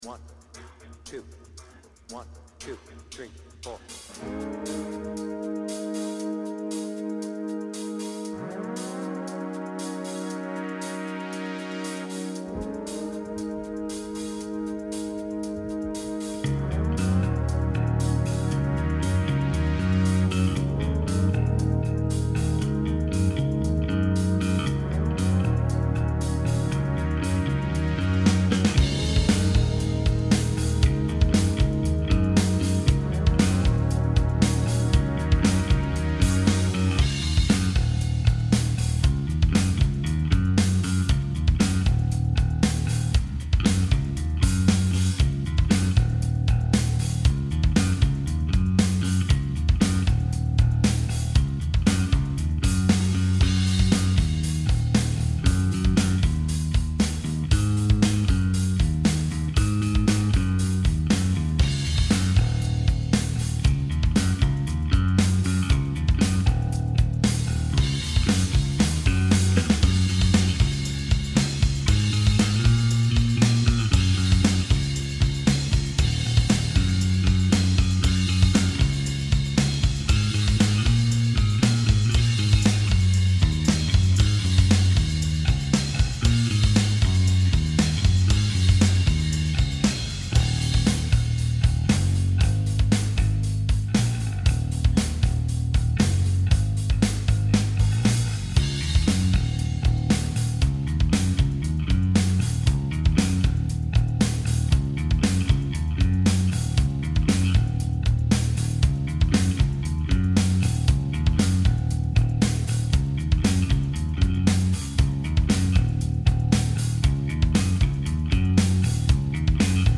BPM : 116
Tuning : Eb
Without vocals